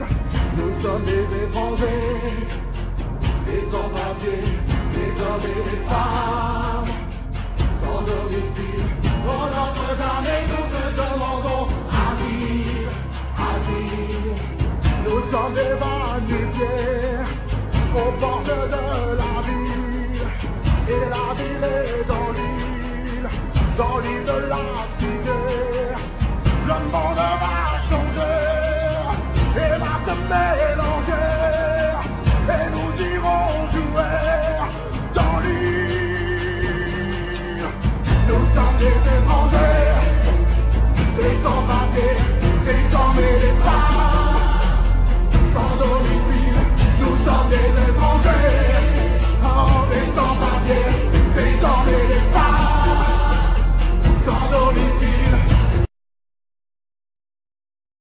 Keyboards
Bass
Guitar
Drums
Percussions